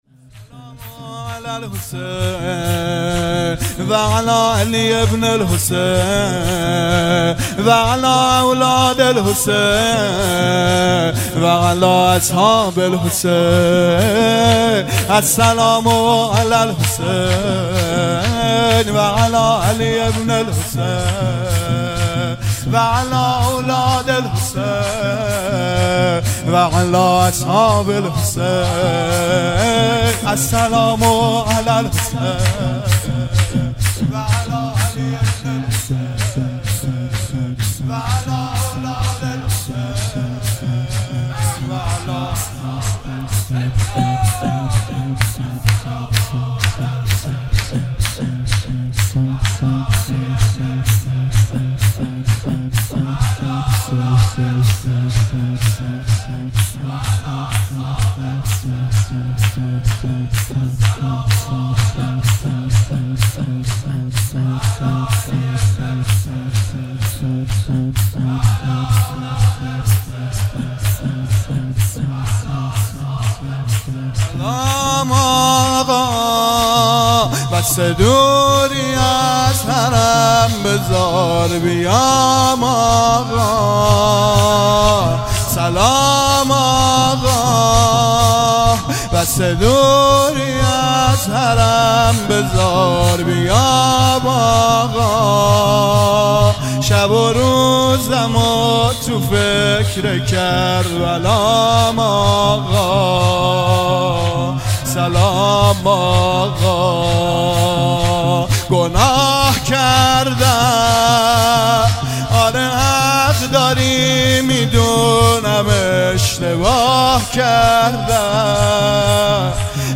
شور| سلام آقا بسه دوری از حرم